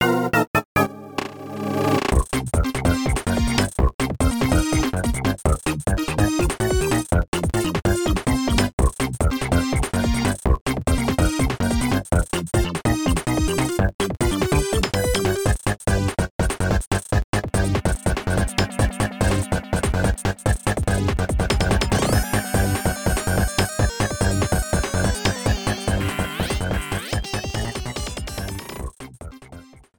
Cropped to 30 seconds, fade out added
Fair use music sample